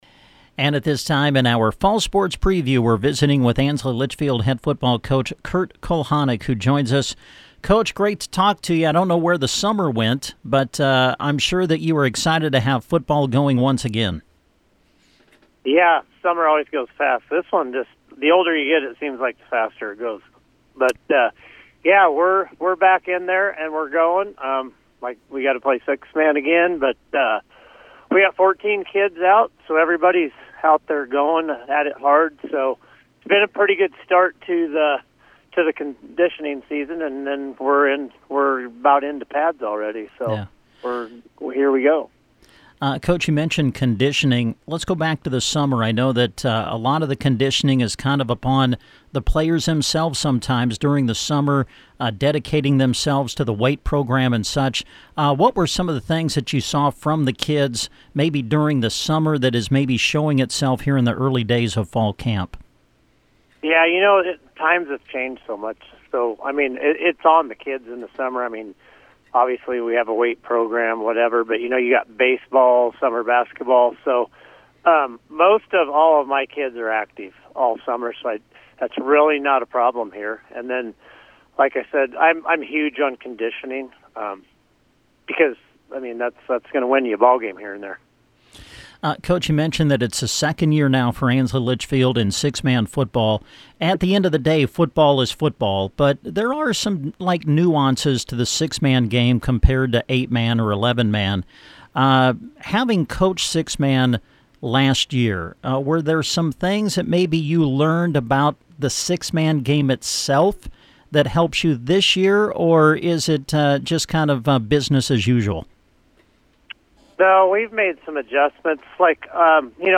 A/L Football Preview – Interview